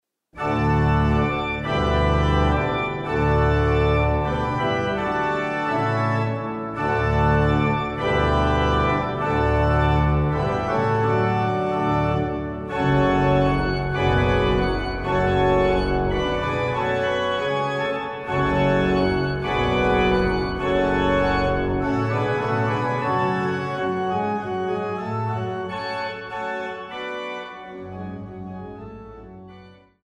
Marienmusik aus dem evangelischen Dom St. Marien zu Wurzen
Orgel